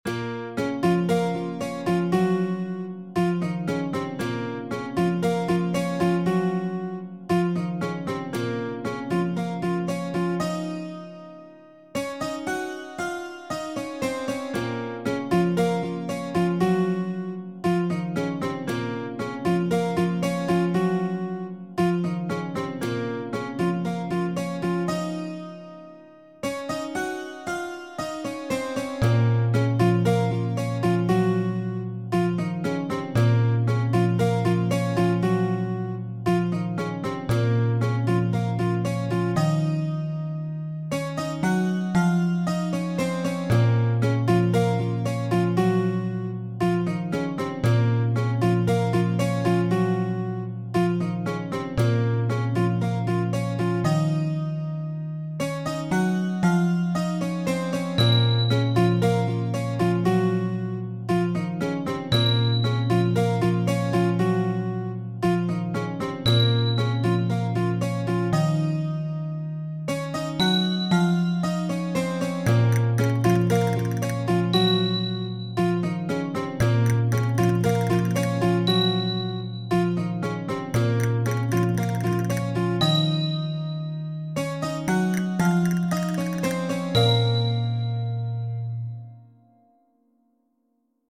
Theater Music